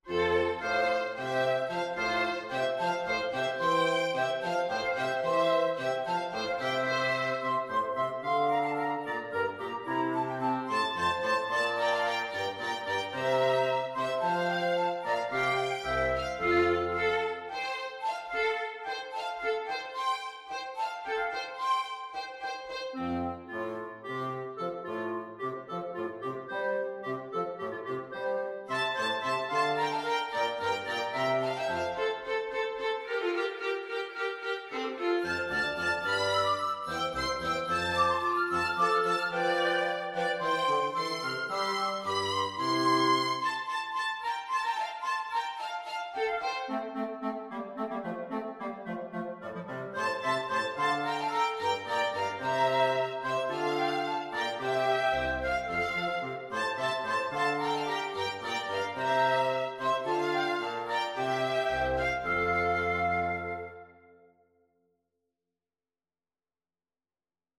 Free Sheet music for Flexible Mixed Ensemble - 4 Players
F major (Sounding Pitch) (View more F major Music for Flexible Mixed Ensemble - 4 Players )
3/2 (View more 3/2 Music)
Classical (View more Classical Flexible Mixed Ensemble - 4 Players Music)